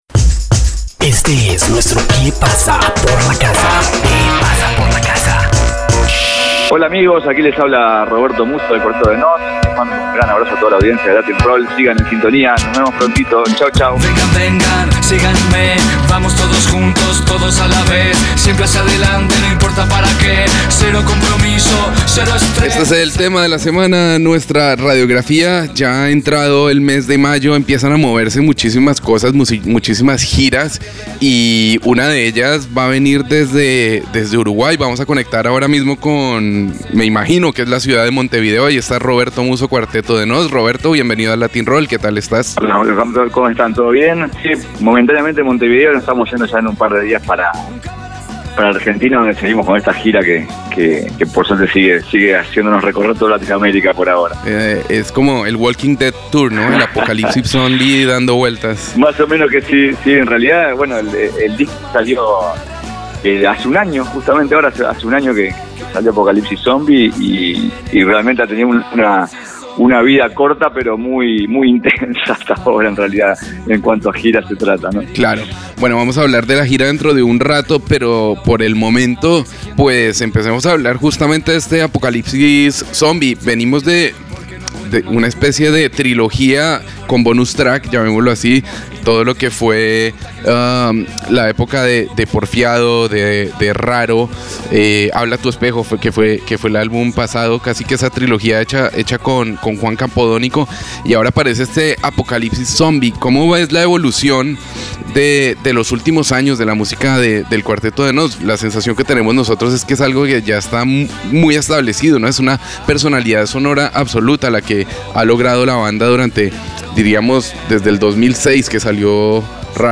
Entrevista exclusiva con Roberto Musso vocalista de la agrupación uruguaya.